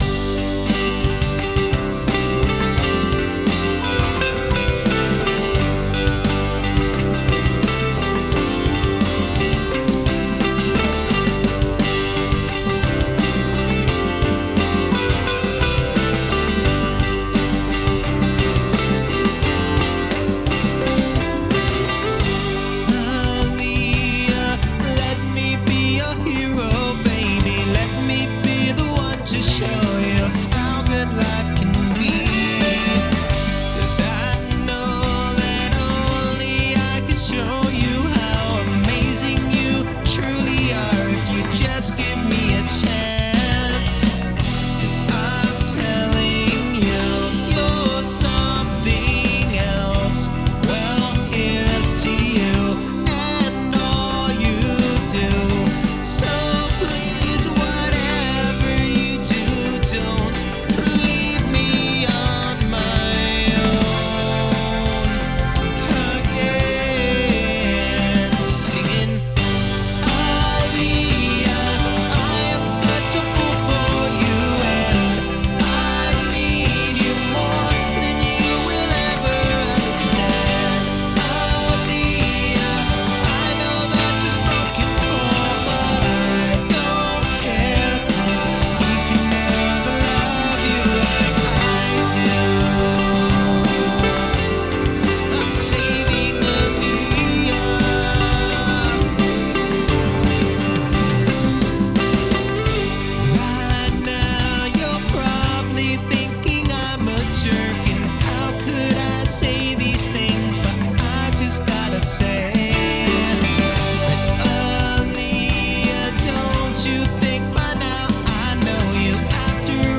Rock
85 BPM
G Major
Vocals, Guitar, Drums, Keyboards
Background Vocals
Bass